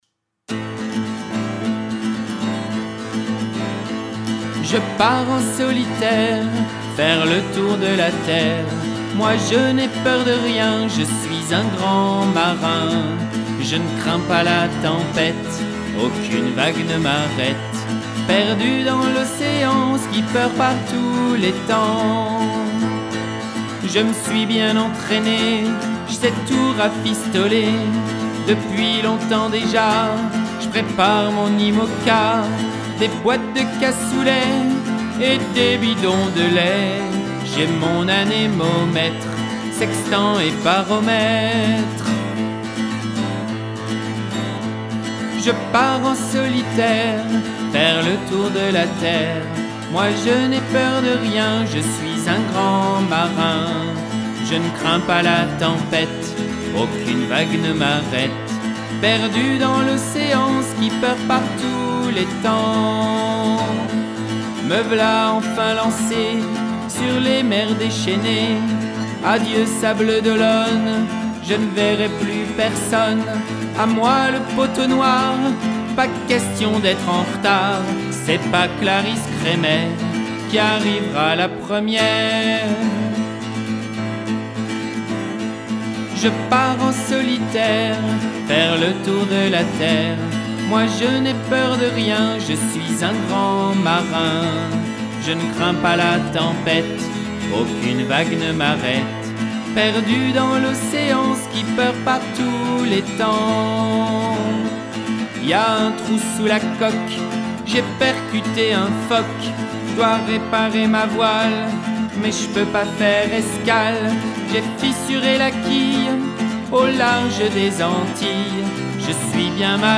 Chanson pour enfants (grands)